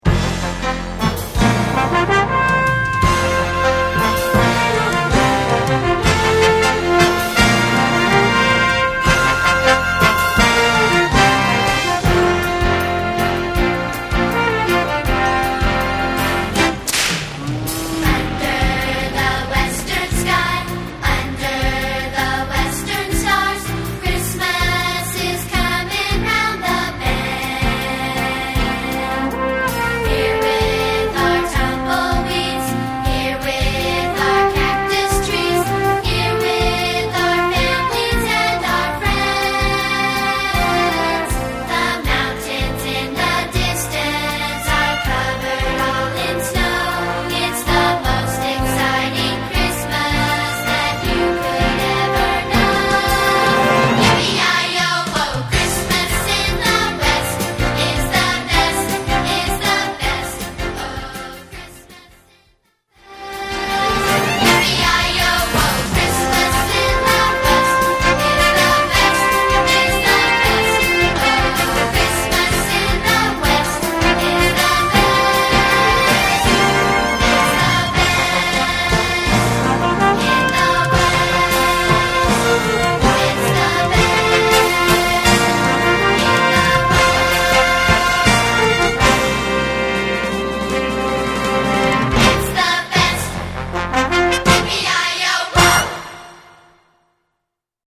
For Young Voices